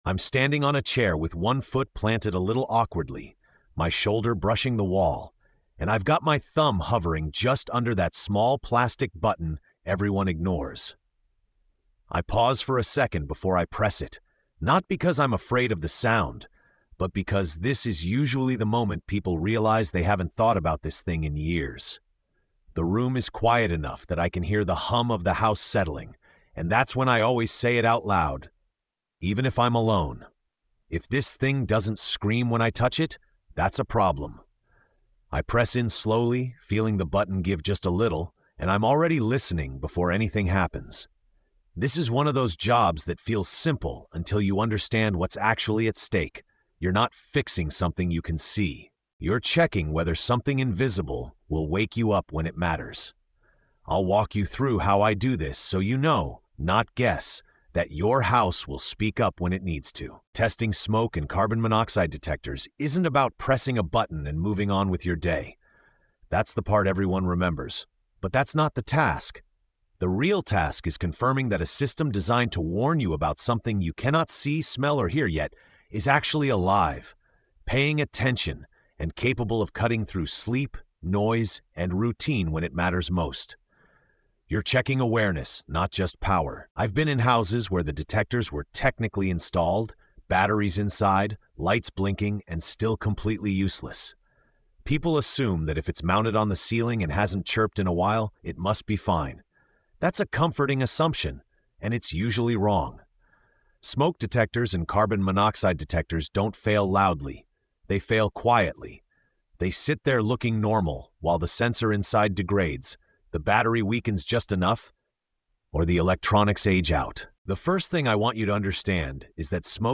Standing beneath the ceiling, listening for a sound that may one day decide whether you wake up or not, the narrator walks the listener through the deeper meaning behind a task most people rush through or forget entirely. With calm authority and lived experience, the episode teaches how to test detectors properly, how to listen for what matters, and how to recognize when “good enough” is no longer safe. The emotional tone is steady, grounded, and serious without being alarmist — a reminder that real protection comes from understanding, not assumption.